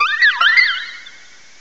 cry_not_comfey.aif